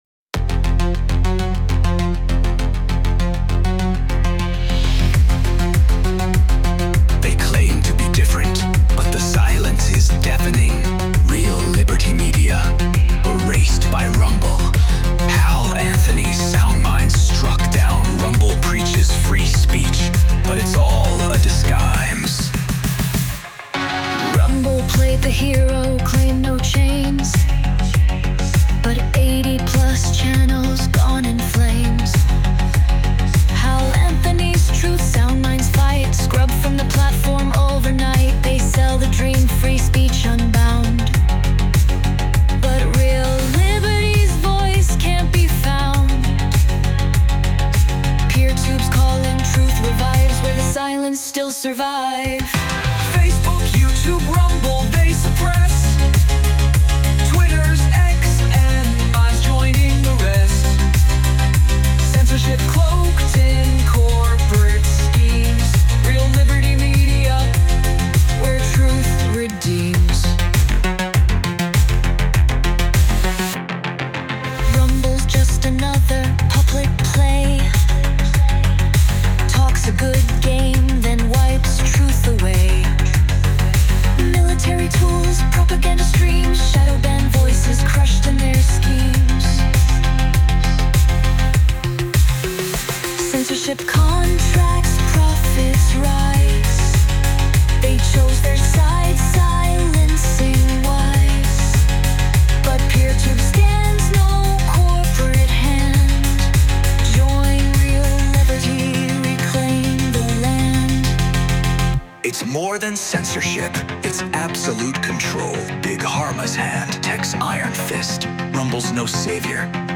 Instrumental - Real Liberty Media Dot XYZ-- 4 mins .mp3